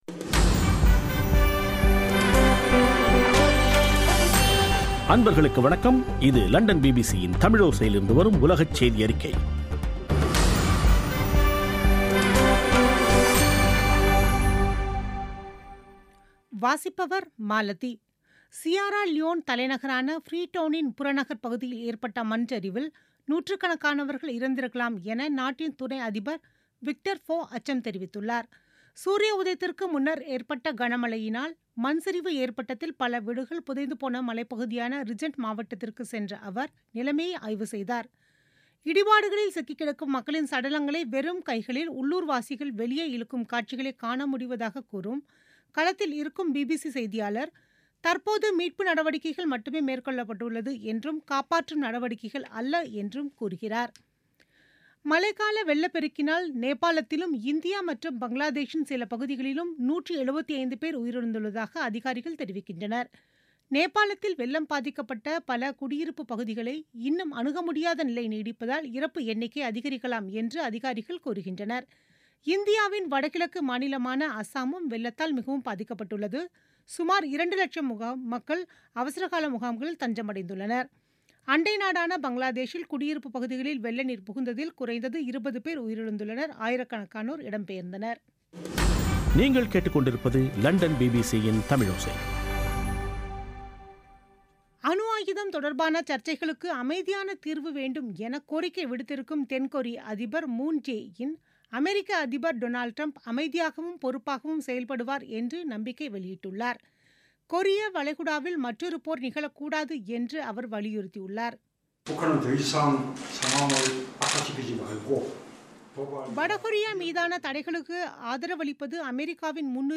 பிபிசி தமிழோசை செய்தியறிக்கை (14/08/2017)